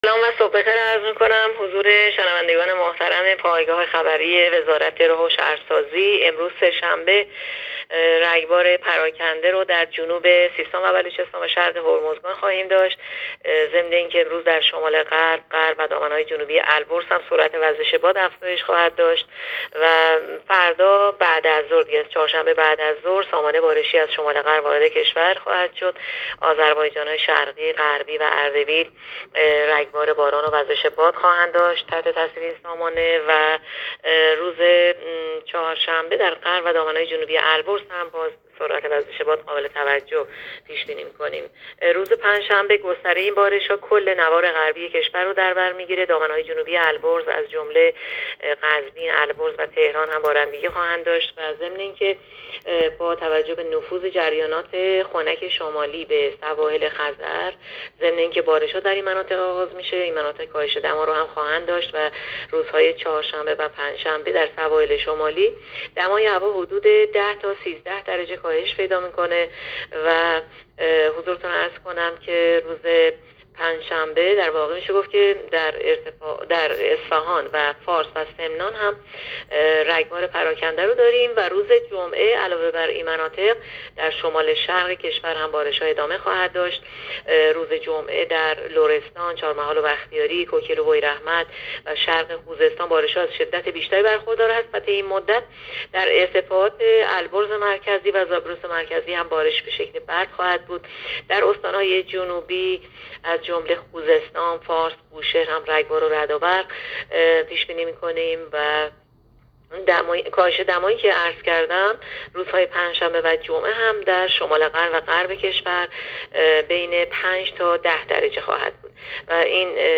گزارش رادیو اینترنتی پایگاه‌ خبری از آخرین وضعیت آب‌وهوای ۲۸ اسفند؛